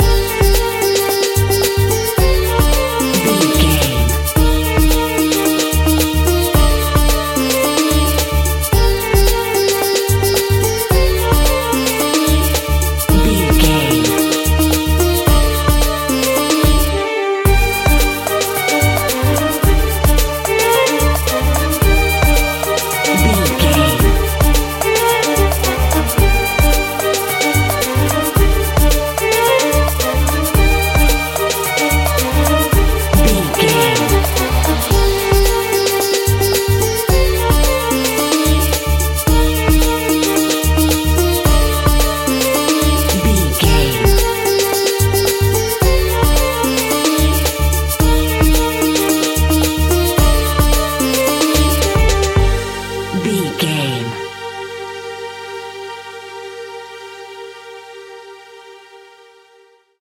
Aeolian/Minor
ethnic
World Music
percussion